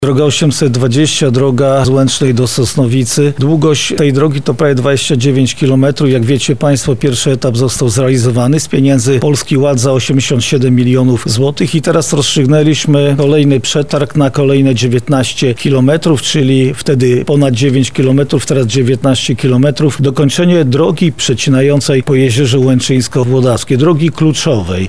O pierwszej inwestycji mówi Marszałek województwa lubelskiego Jarosław Stawiarski: